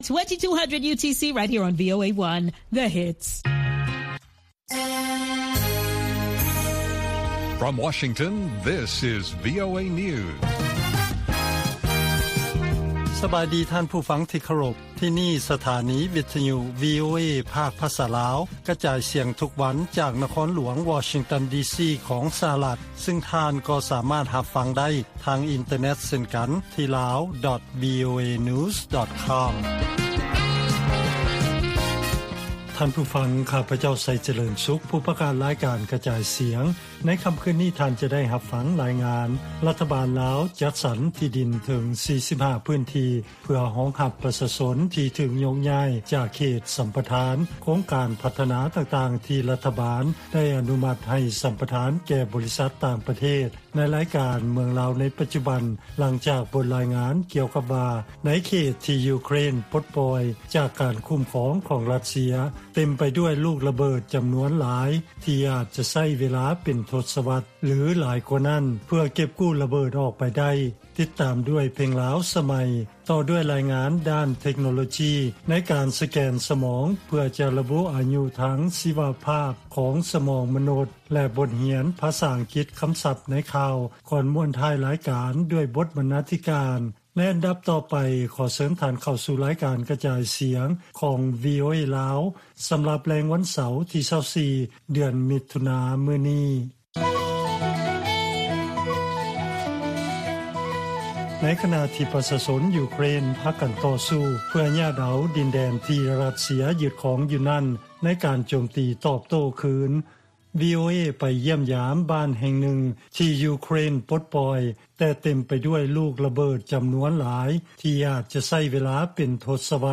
ລາຍການກະຈາຍສຽງຂອງວີໂອເອ ລາວ: ໝູ່ບ້ານຂອງຊາວຢູເຄຣນ ກອງເຕັມໄປດ້ວຍລະເບີດ ລຸນຫຼັງການຢຶດຄອງຂອງ ຣັດເຊຍ